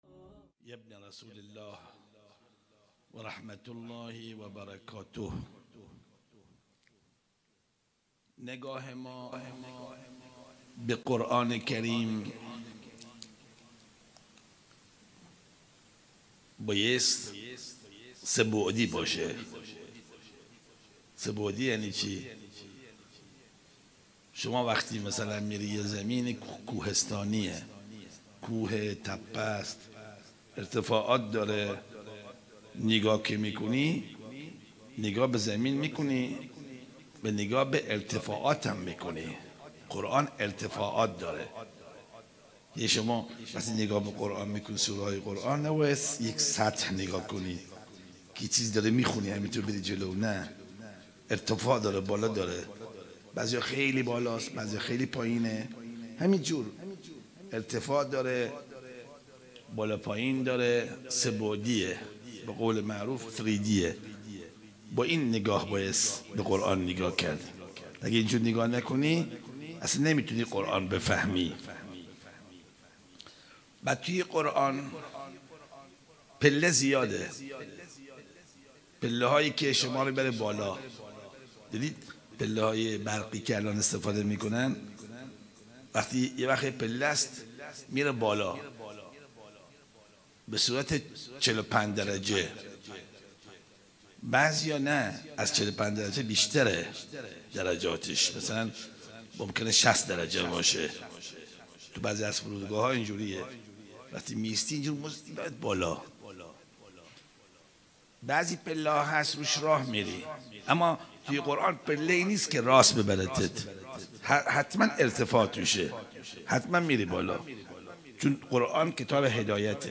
سخنرانی
شب نهم محرم 1443 ه.ق